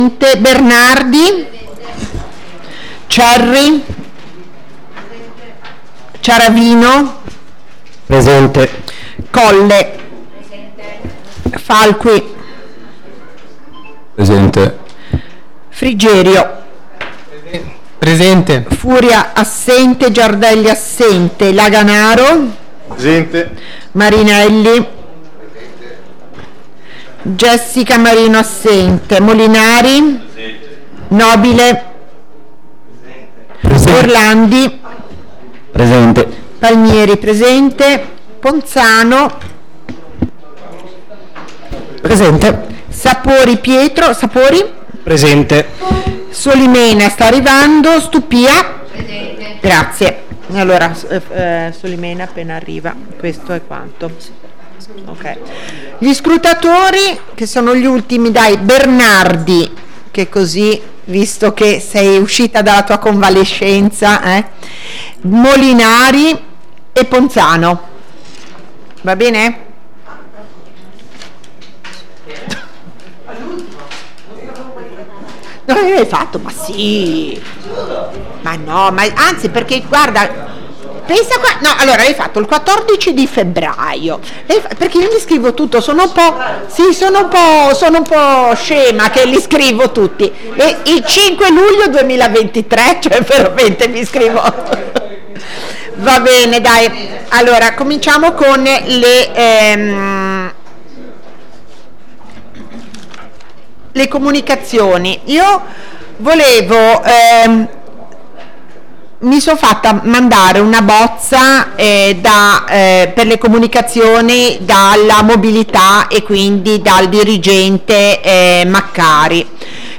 Seduta del Consiglio Municipale LUNEDI' 31 MARZO alle ore 17:30 | Genova contents.